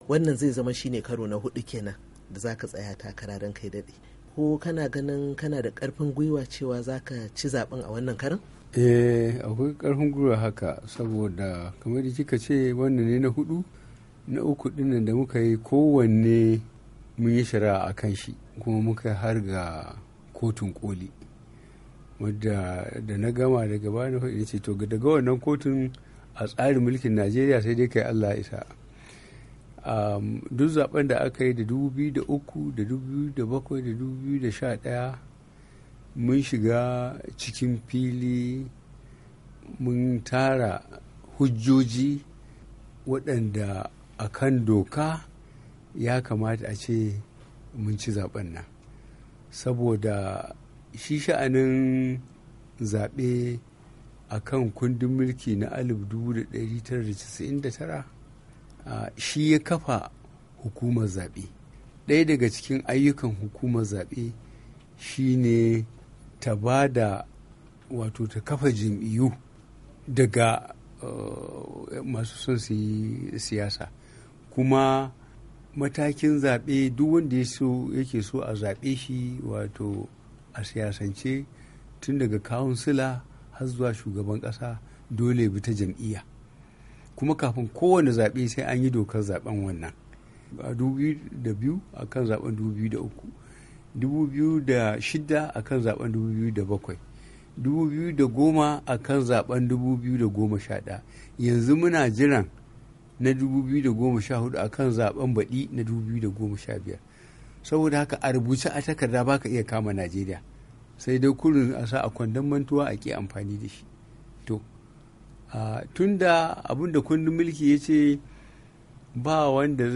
A wata fira da yayi da wakiliyar Muryar Amurka Janaral Muhammed Buhari ya fito fili ya bayyana aniyar shiga zaben 2015 karo na hudu.